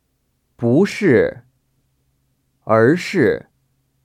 [búshì...érshì...] 부스...얼스  ▶